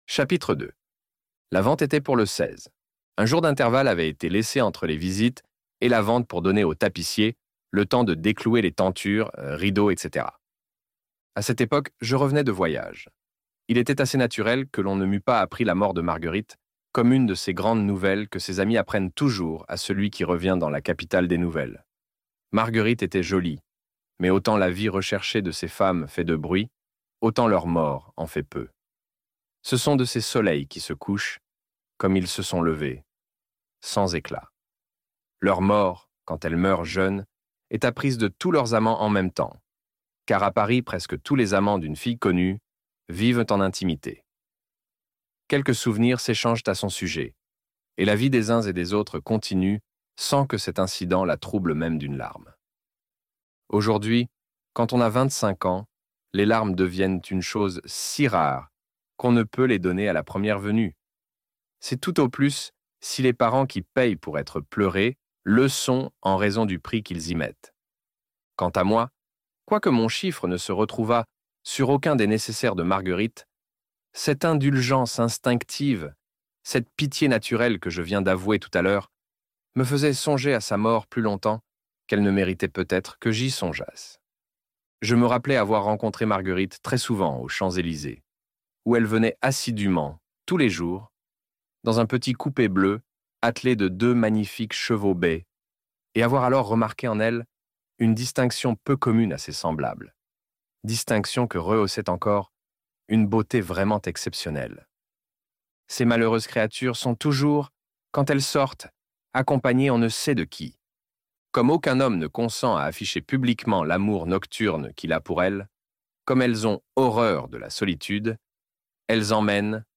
La Dame aux Camélias - Livre Audio